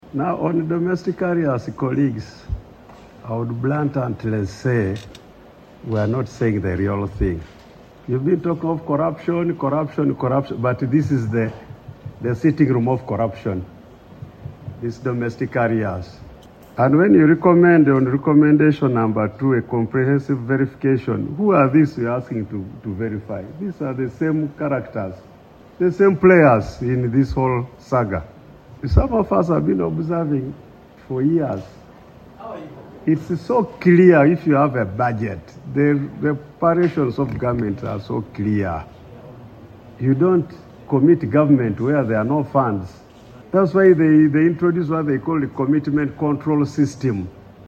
The revelation was made by the Chairperson of the Budget Committee, Hon. Patrick Isiagi Opolot, during consideration of the report from the Committee on Finance, Planning and Economic Development.
AUDIO: Hon. Patrick Isiagi Opolot
Isiagi on domestic arrears .mp3